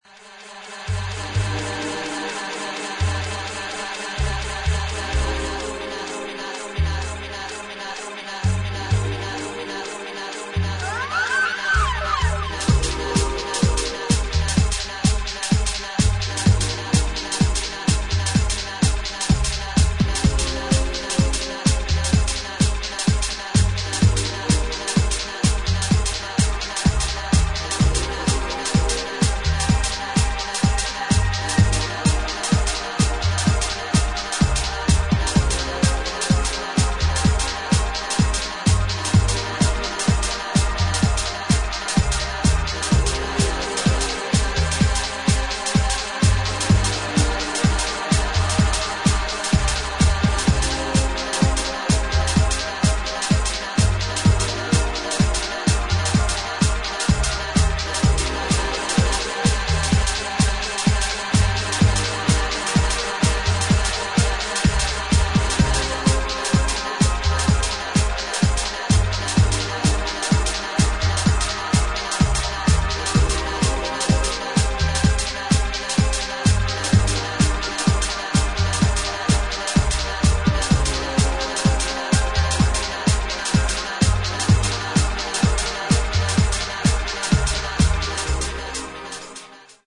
反復するボイスサンプルや移り変わるシンセワークの一体感で、情緒あふれる世界観を構築した